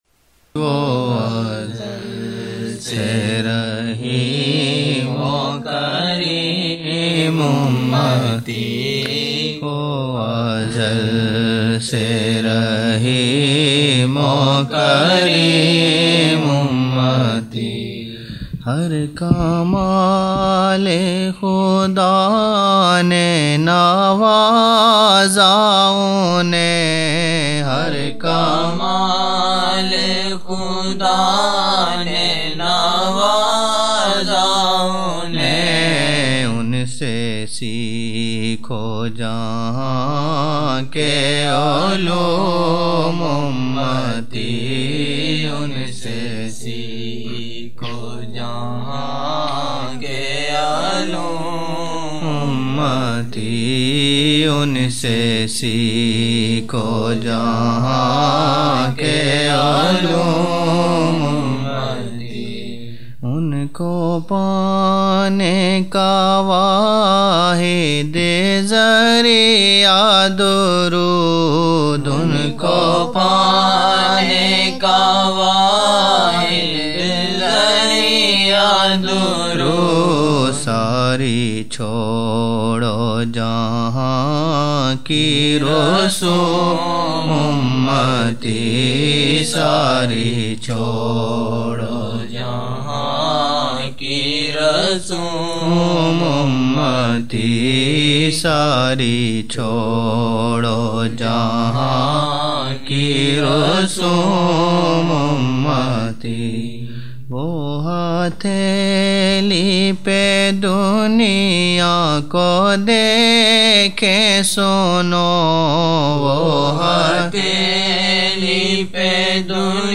26 November 1999 - Jumma mehfil (18 Shaban 1420)